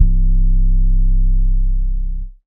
Wish_and_Dare_808.wav